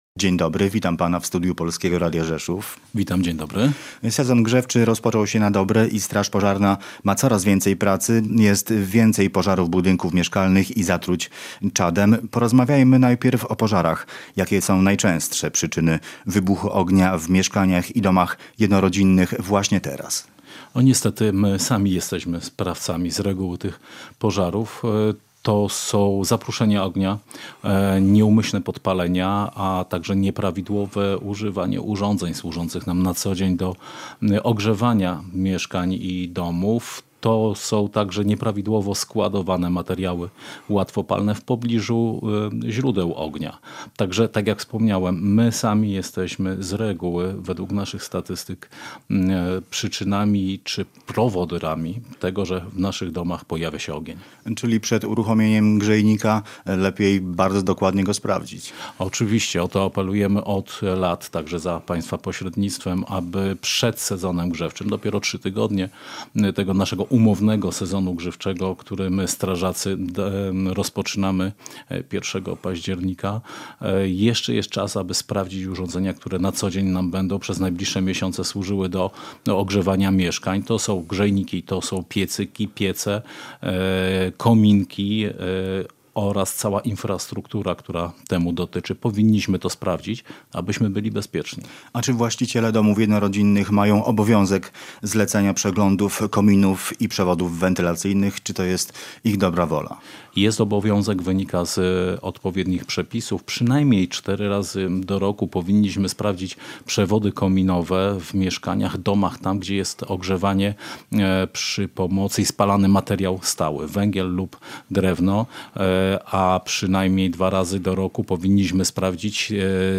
Gość dnia • Na Podkarpaciu rośnie liczba pożarów i zatruć tlenkiem węgla.